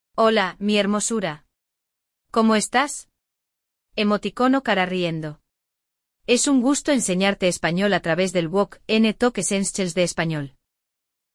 Hoje, você vai ouvir a história de dois argentinos, uma cliente e um garçom.